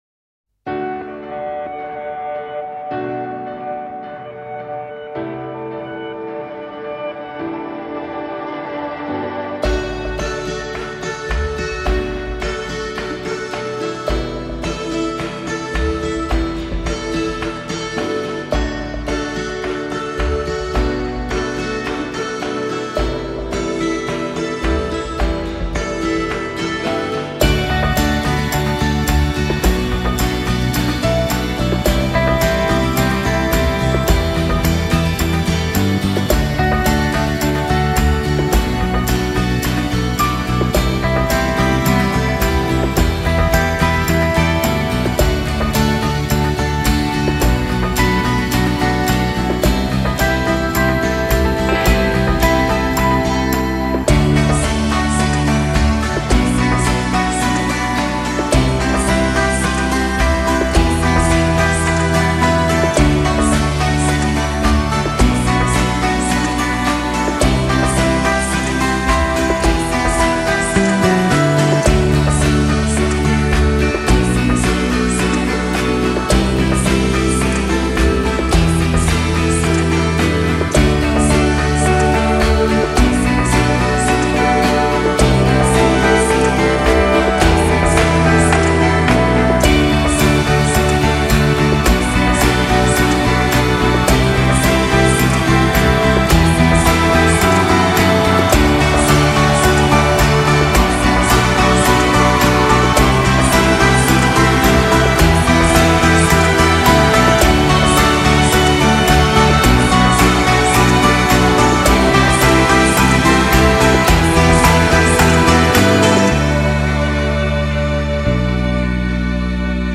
Largo [0-10] melancolie - piano - - -